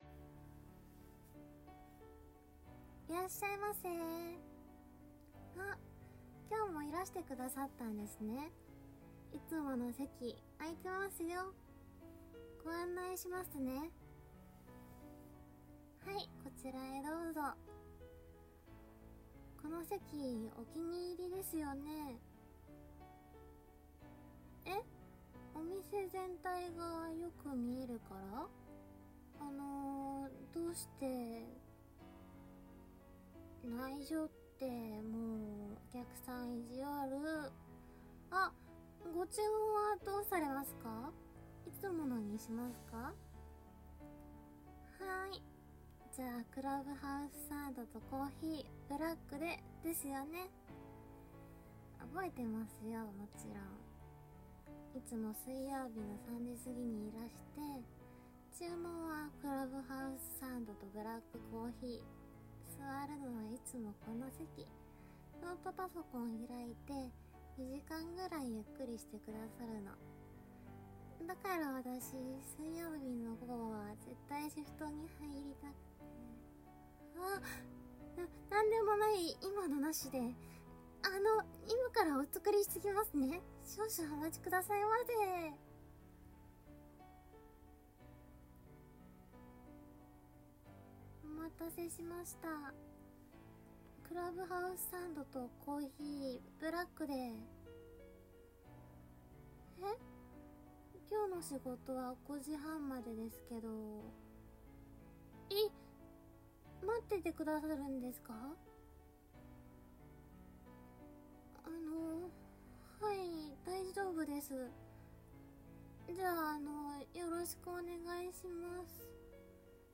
【超癒し系】気になるカフェ店員とおしゃべり【シチュエーションボイス】